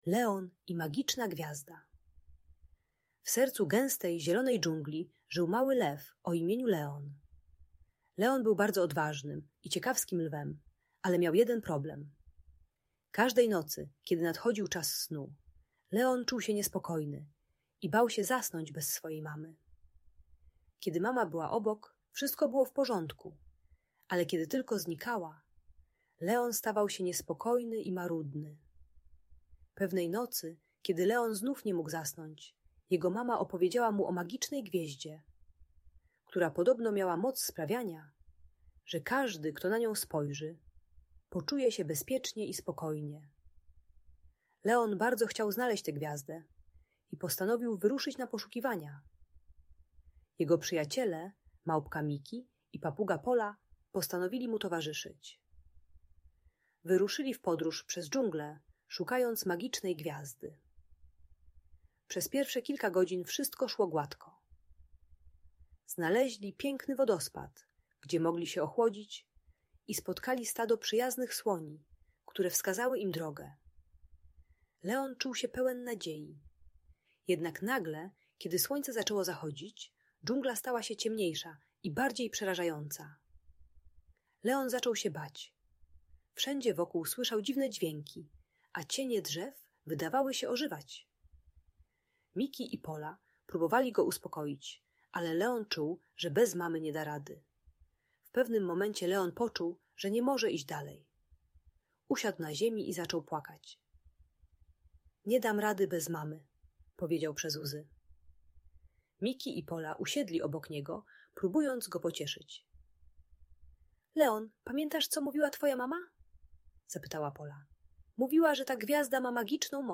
Leon i Magiczna Gwiazda - Audiobajka